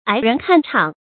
矮人看場 注音： ㄞˇ ㄖㄣˊ ㄎㄢ ㄔㄤˇ 讀音讀法： 意思解釋： 比喻只知道附和別人，自己沒有主見。